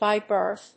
アクセントby bírth